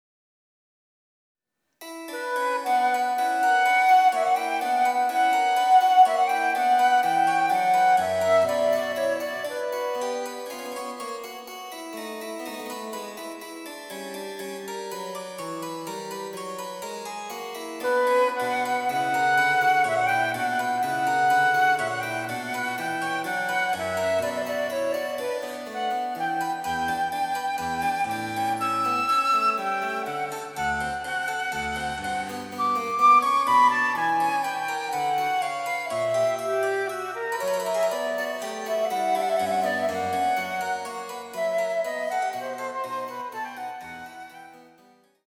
この曲の場合も、つねに着実に前に進む感じがあります。
■フルートによる演奏